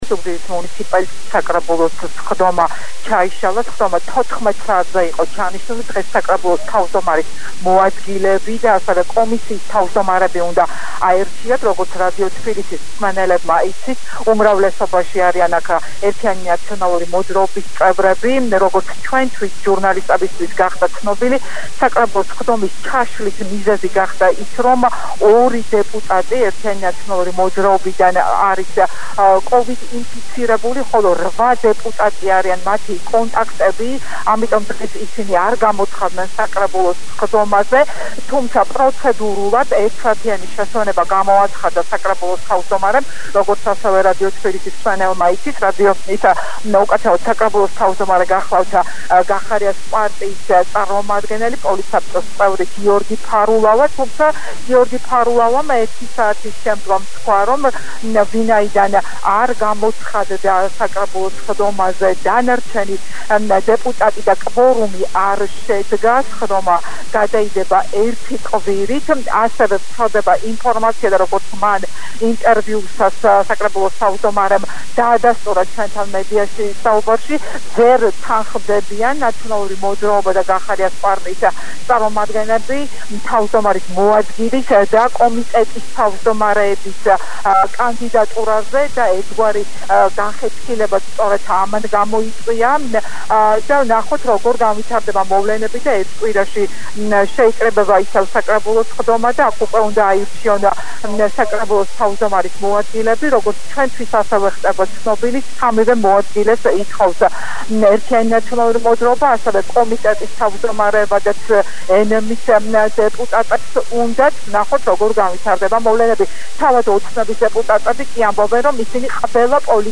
ჩართვას ახალ ამბებში: